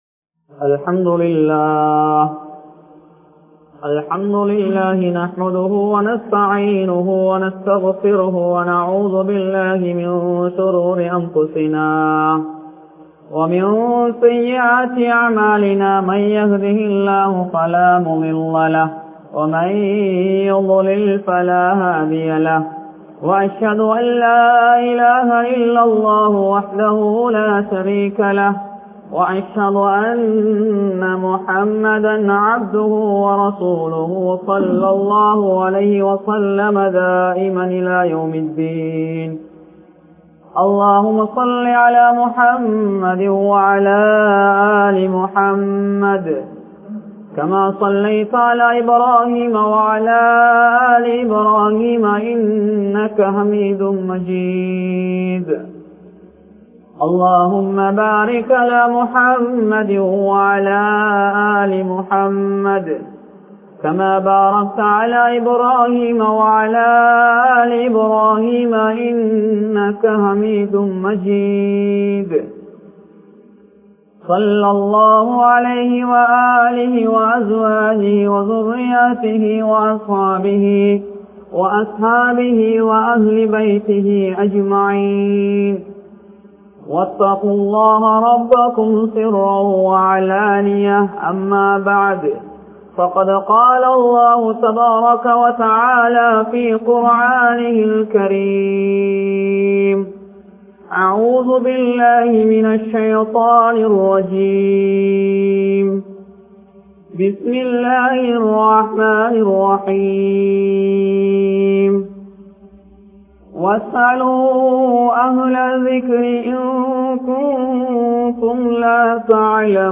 Maarkaththitku Munnurimai Kodungal (மார்க்கத்திற்கு முன்னுரிமை கொடுங்கள்) | Audio Bayans | All Ceylon Muslim Youth Community | Addalaichenai
Town Jumua Masjidh